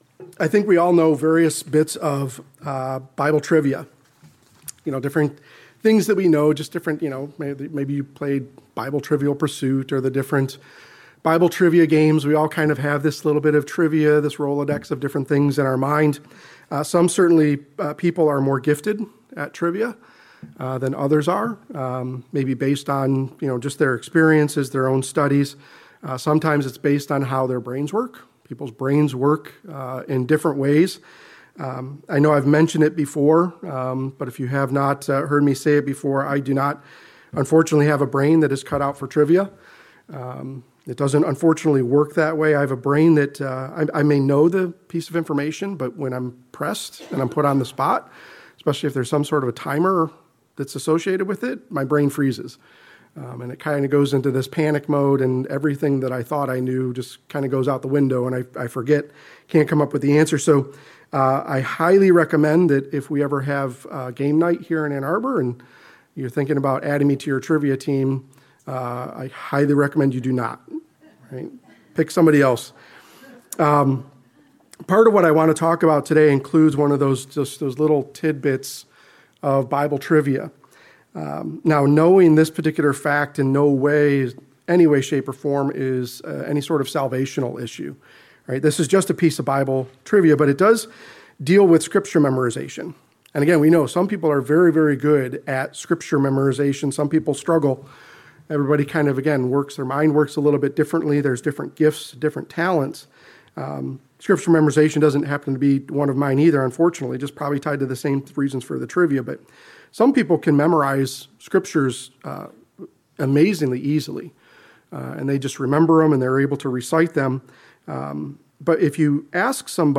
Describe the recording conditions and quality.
Given in Ann Arbor, MI